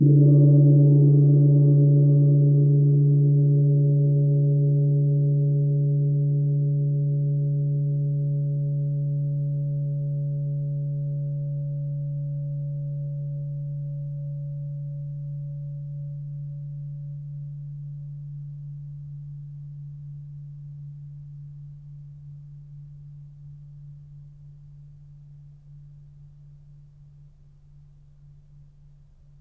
gongHit_p.wav